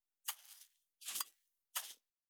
366,調味料固形物,カシャカシャ,サラサラ,パラパラ,ジャラジャラ,サッサッ,ザッザッ,シャッシャッ,シュッ,パッ,
効果音厨房/台所/レストラン/kitchen